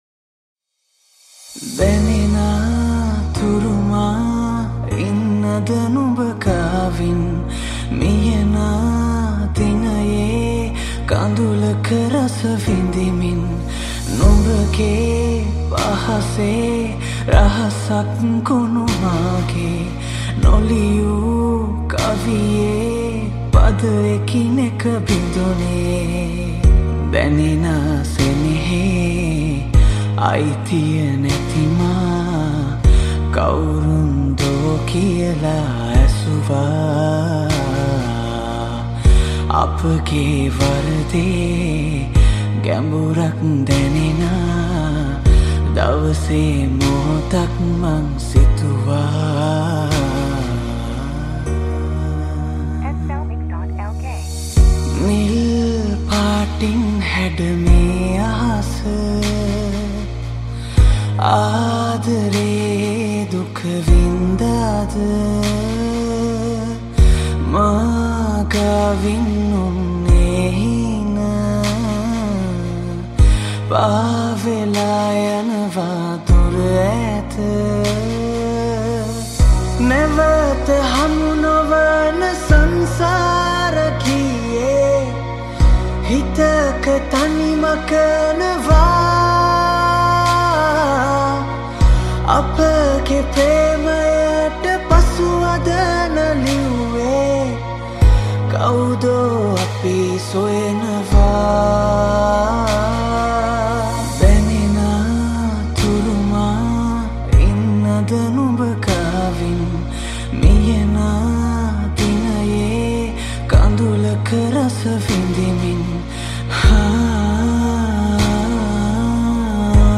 Guitar
Veena
Flute